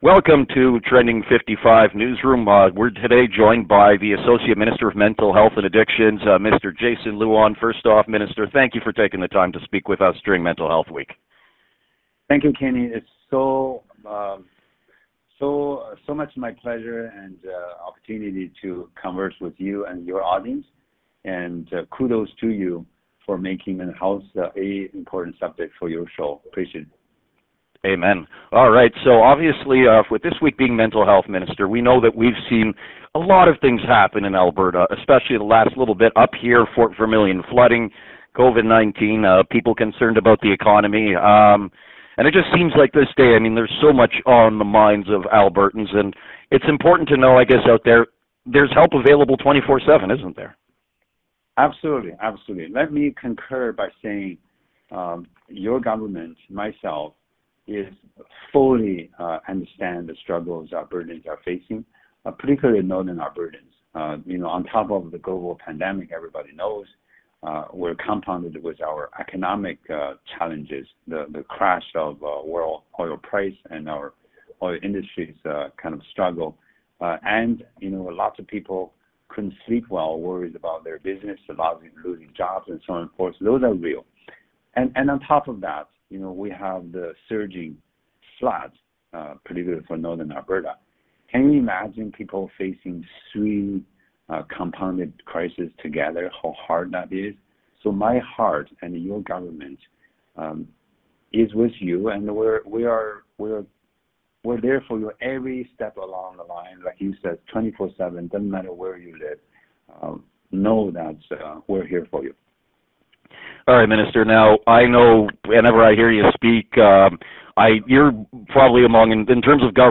The Trending 55 Newsroom had a chance to speak with Associate Minister for Mental Health and Addictions, Jason Luan.
The newsroom’s conversation with Associate Minister Luan is below.